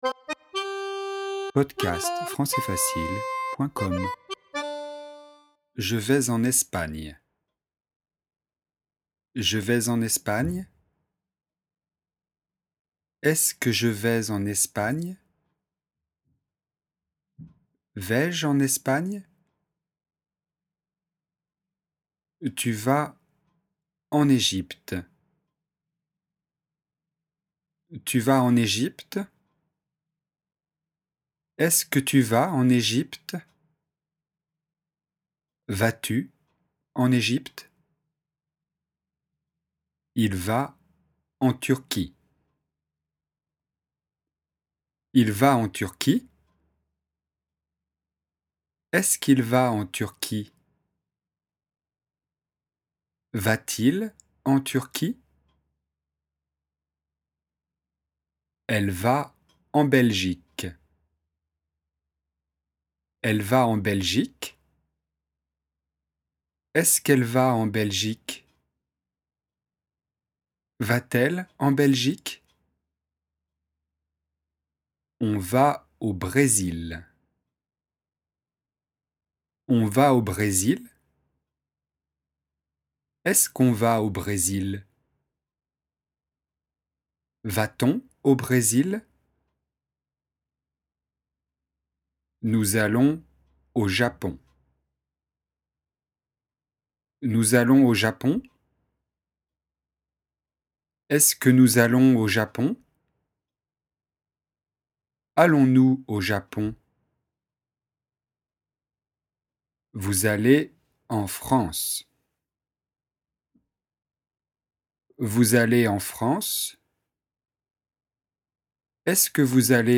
⚠ La voix monte à la fin.